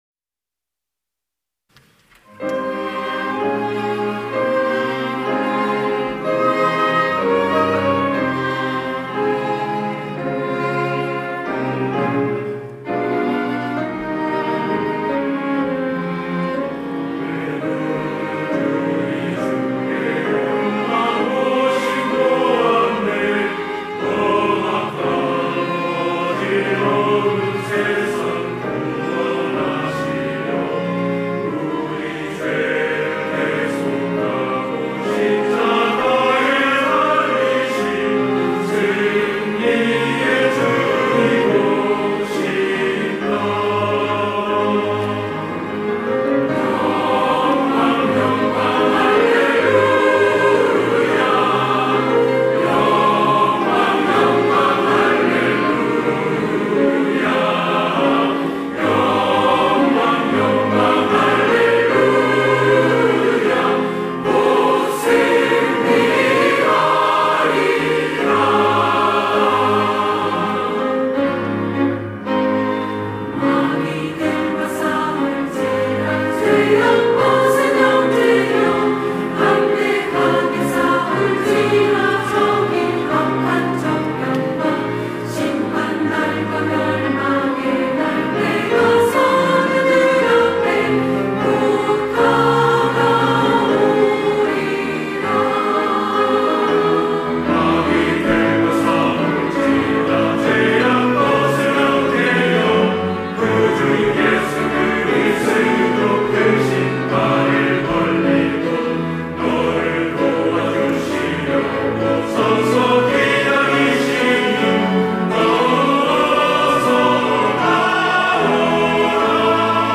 호산나(주일3부) - 승전가